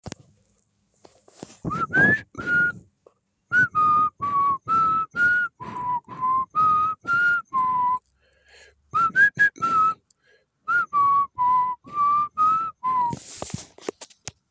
Podsyłam wersję "gwizdaną"' :))...
Nutka w stylu indie-pop
Elektroniczno-houseowy bit z ładnym, kobiecym wokalem.
gwizd.m4a